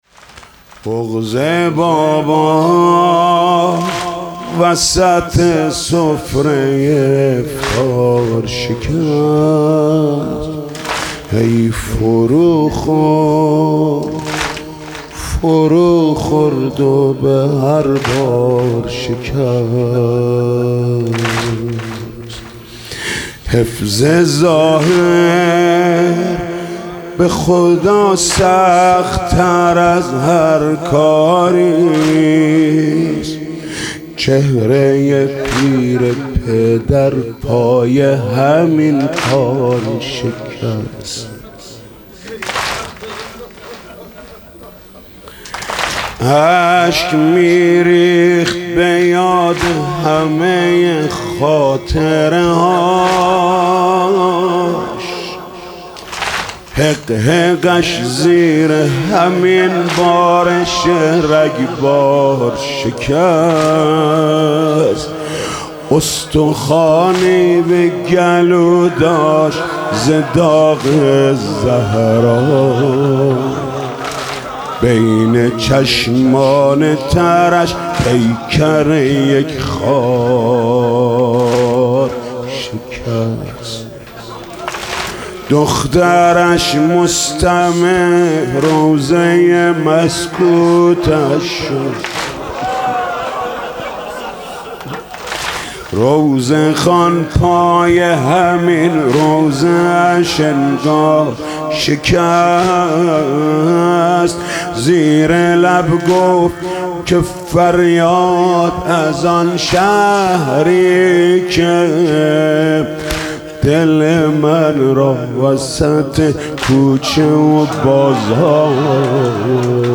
گزارش صوتی شب بیستم ماه رمضان در هیئت رایةالعباس(ع)
بخش اول - مناجات ( عمرم گذشت در تب و تاب ندیدنت )
بخش دوم - روضه ( به عزم فتح خیبر رفت )
بخش سوم - زمینه ( از خون فرق مولامون محراب کوفه پر خونه )